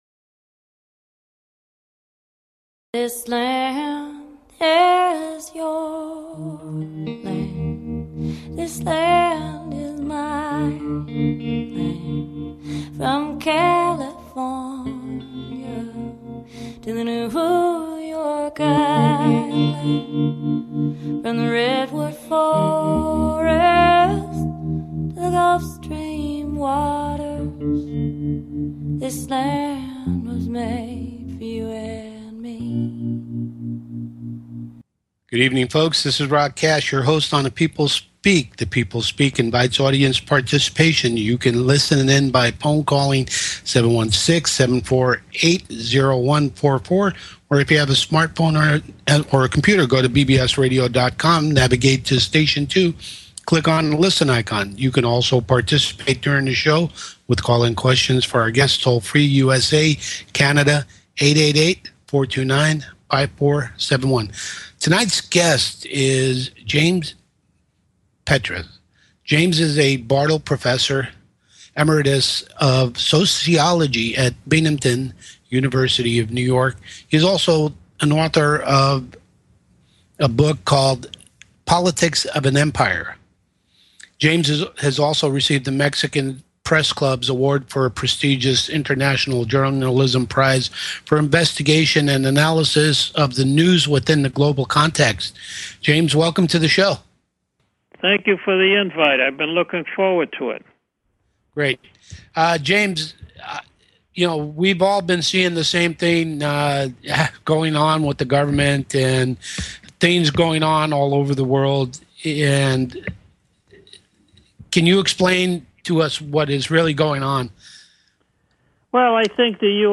Talk Show Episode
Guest, James Petras